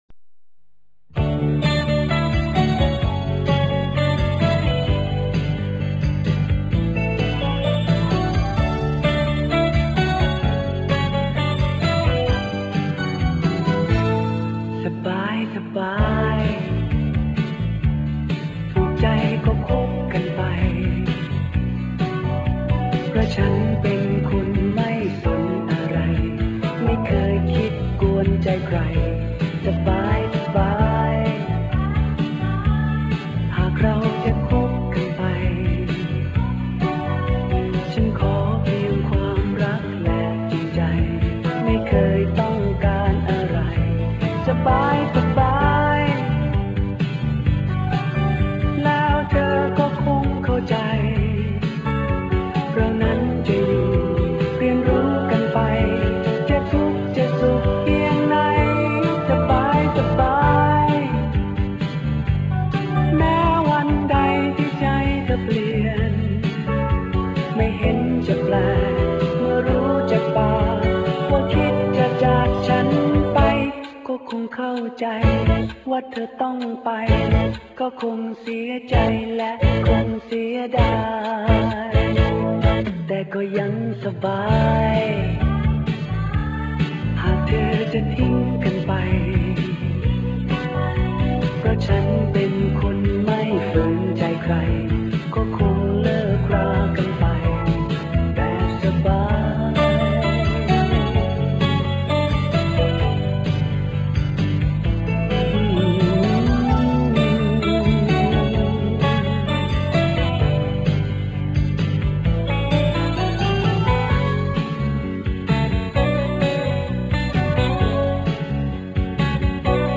(This song can be played on SEAsite under “Pop Music”)